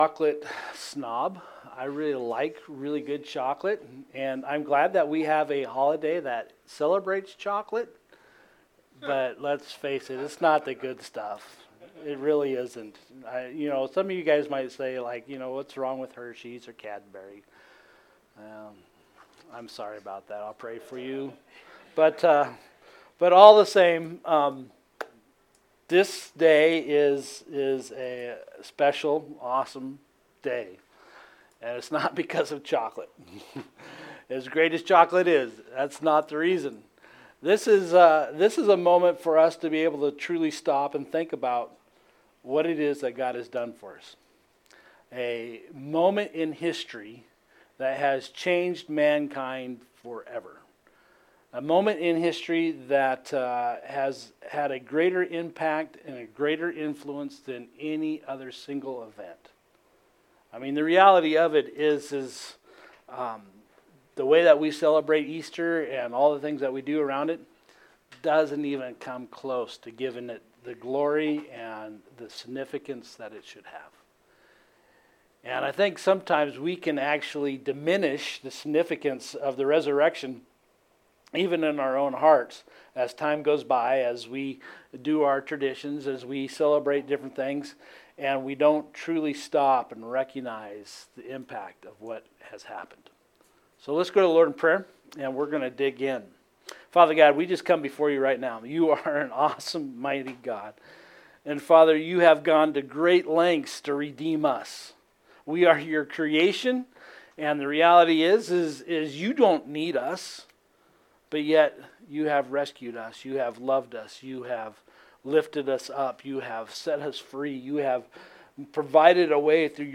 Easter Service